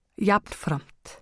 framburður